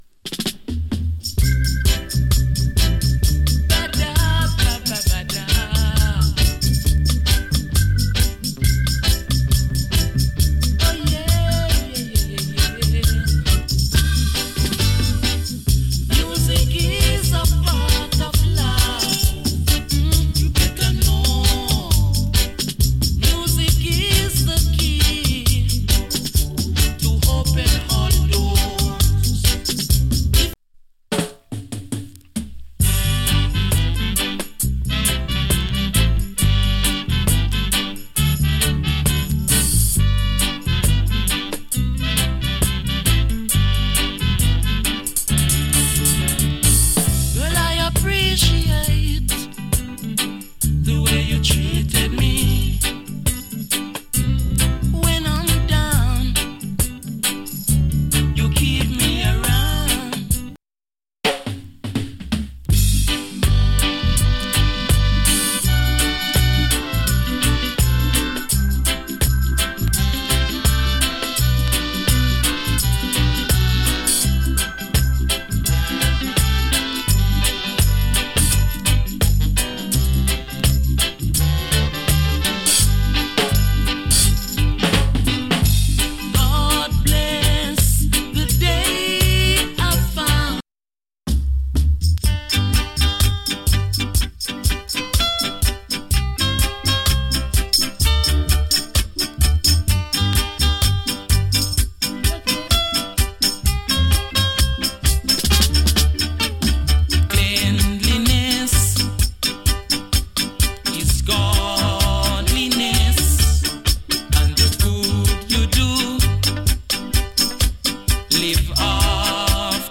極わずかにチリ、パチノイズ有り。
ROOTS REGGAE ALBUM